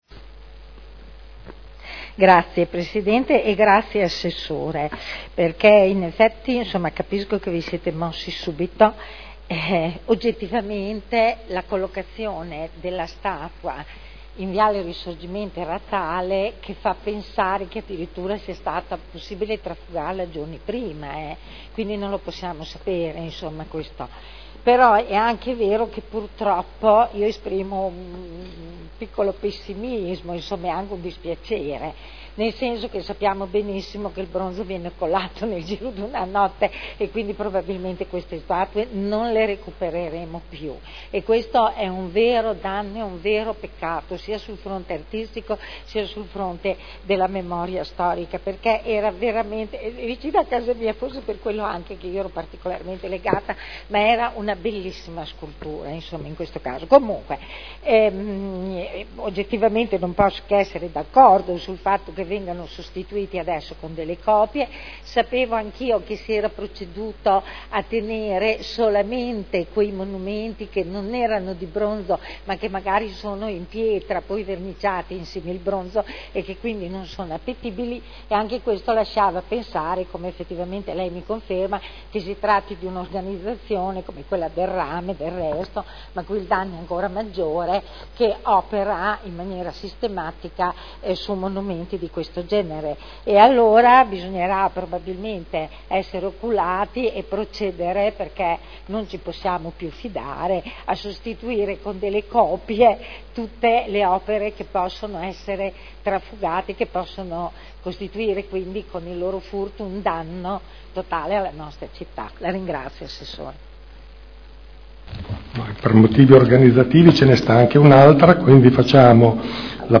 Seduta del 01/10/2012 Replica ad Ass. Marino. Interrogazione della consigliera Rossi E. (IdV) avente per oggetto: “Furto sculture” (presentata il 2 aprile 2012 – in trattazione l’1.10.2012)